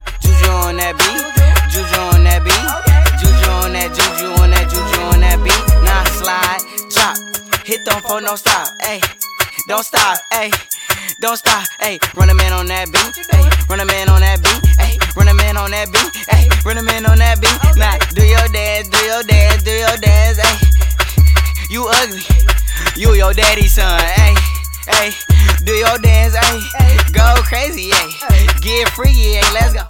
• Hip-Hop